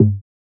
{Snr} outside type(1).wav